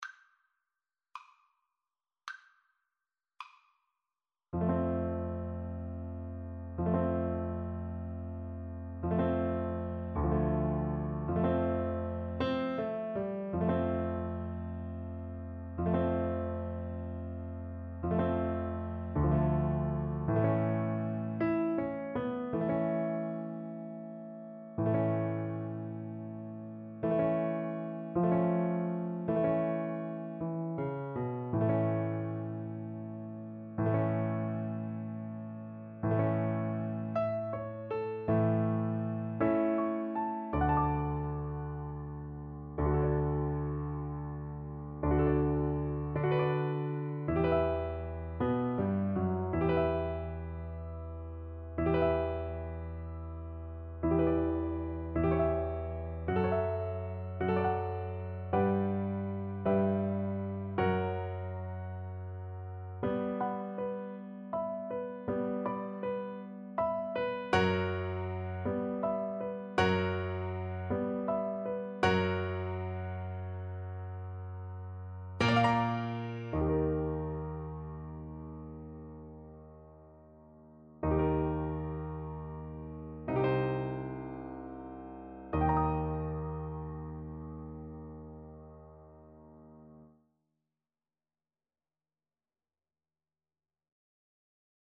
Andante
6/8 (View more 6/8 Music)
Classical (View more Classical Trombone Music)
Relaxing Music for Trombone